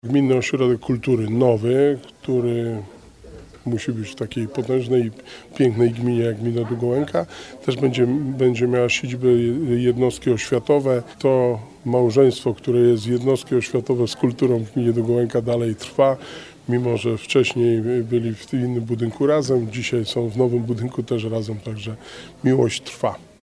Ta inwestycja była potrzebna Długołęce, mówi Wojciech Błoński, wójt gminy Długołęka.